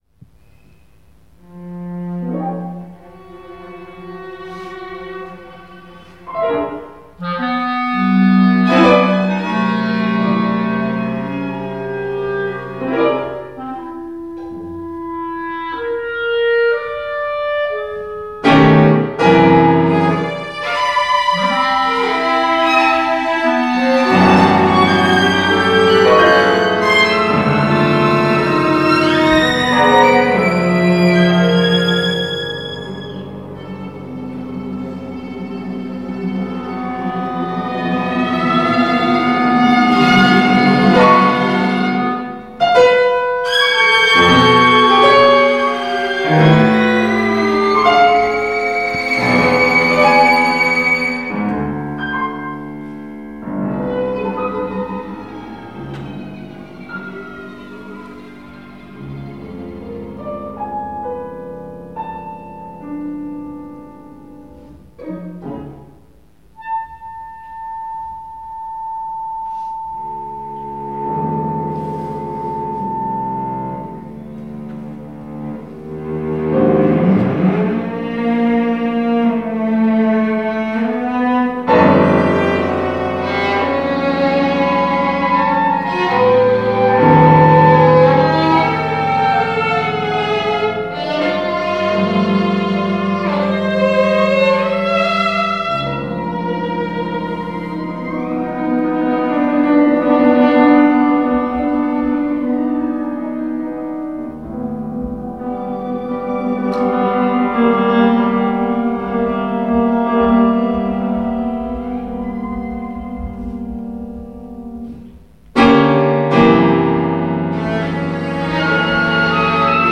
pour clarinette violon violloncello et piano
クラリネット、バイオリン、チェロ、ピアノのための   8min.